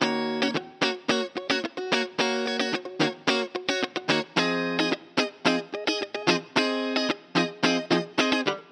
03 Guitar PT2.wav